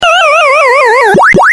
Effets Sonores